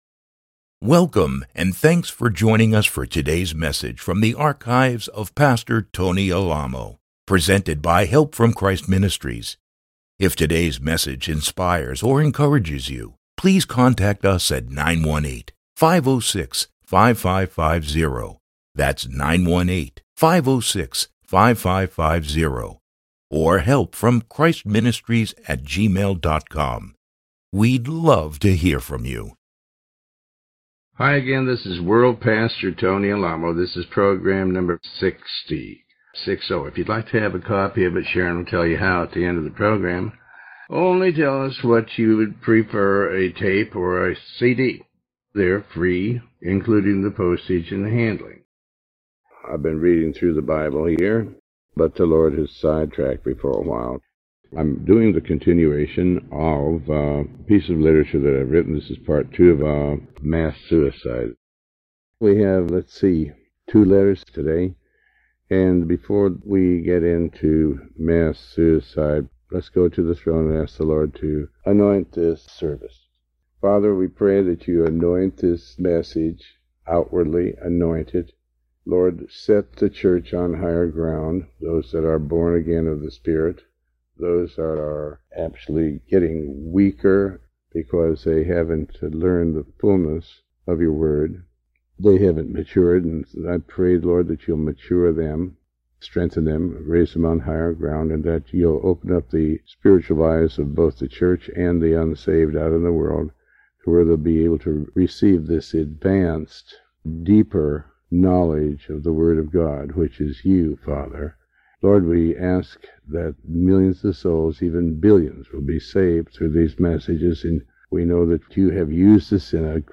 Sermon 60A